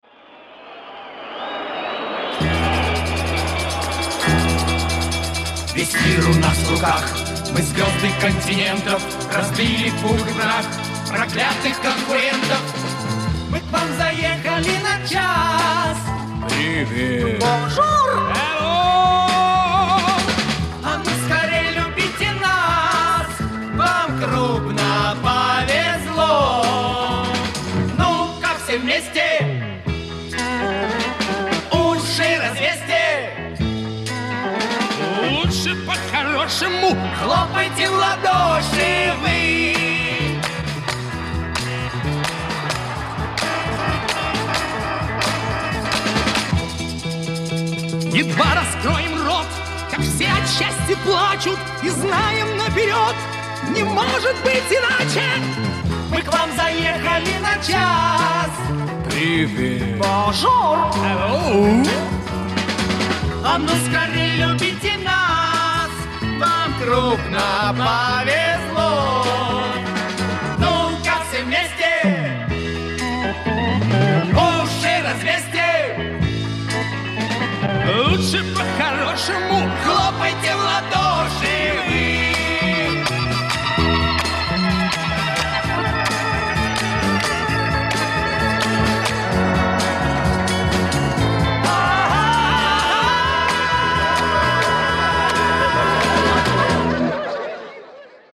• Категория: Детские песни
песни из мультфильмов, советские детские песни